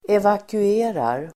Uttal: [evaku'e:rar]